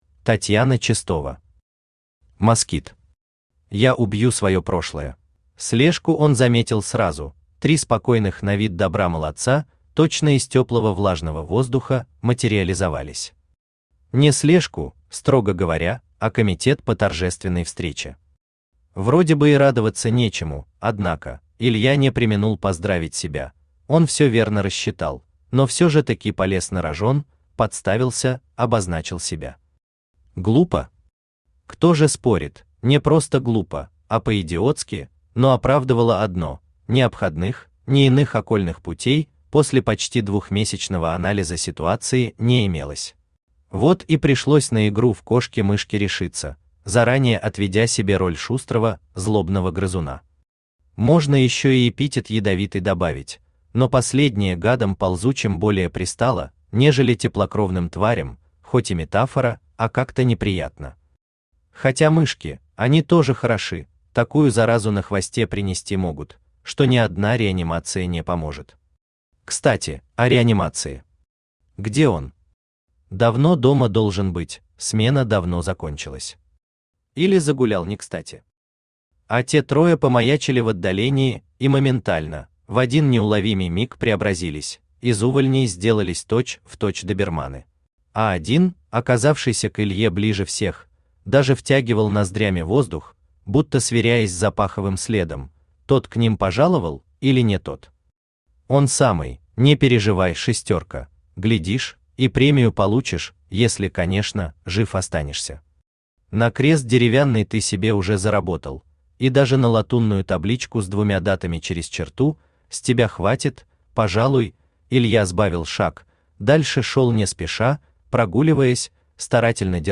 Аудиокнига Москит. Я убью свое прошлое | Библиотека аудиокниг
Я убью свое прошлое Автор Татьяна Чистова Читает аудиокнигу Авточтец ЛитРес.